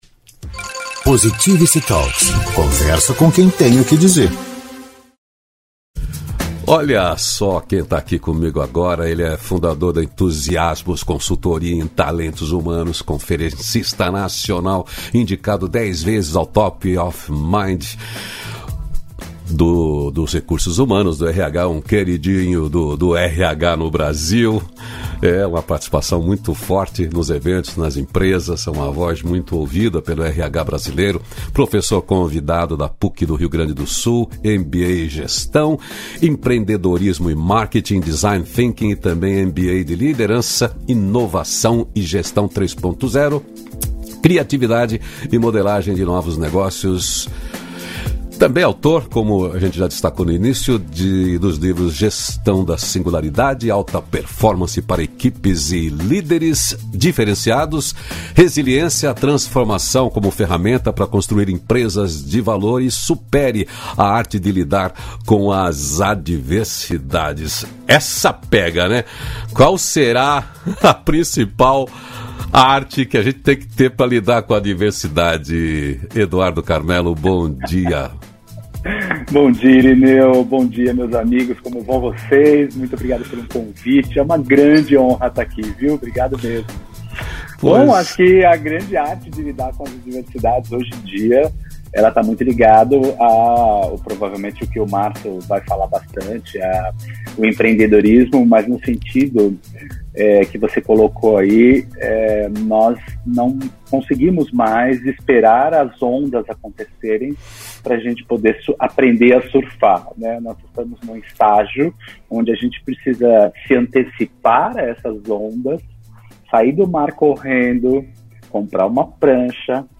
250-feliz-dia-novo-entrevista.mp3